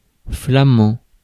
Ääntäminen
Synonyymit néerlandais hollandais langue de Vondel pain suisse Ääntäminen France: IPA: [fla.mɑ̃] Haettu sana löytyi näillä lähdekielillä: ranska Käännös Konteksti Ääninäyte Adjektiivit 1.